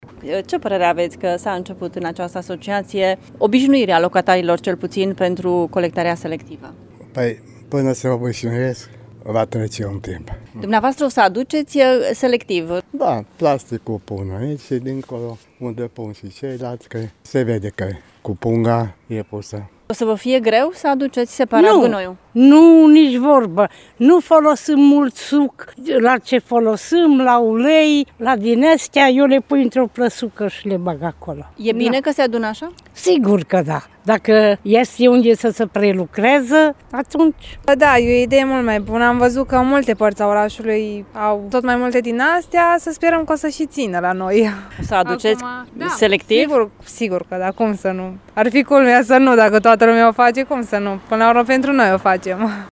Locatarii din zonă salută inițiativa și spun că nu este greu să se obișnuiască cu colectarea selectivă: